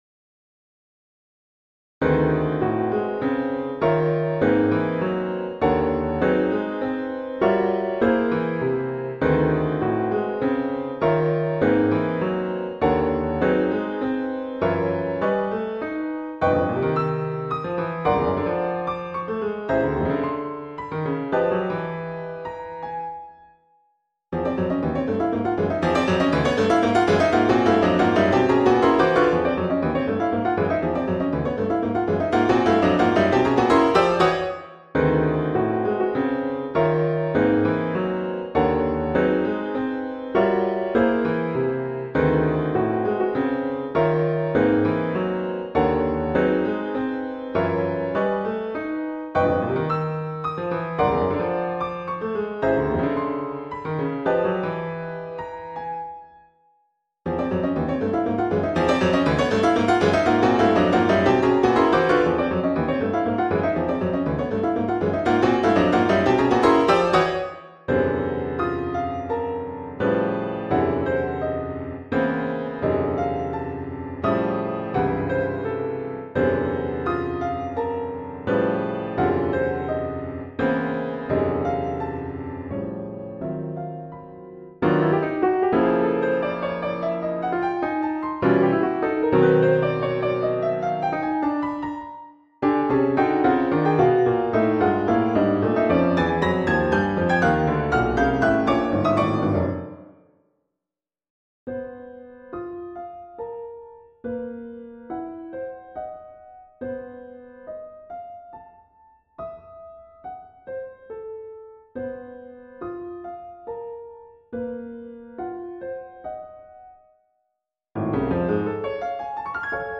Works for automatic piano(1998)  8:27